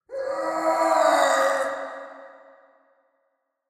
GuardScream2.ogg